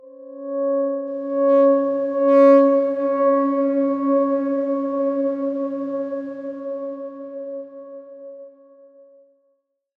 X_Darkswarm-C#4-f.wav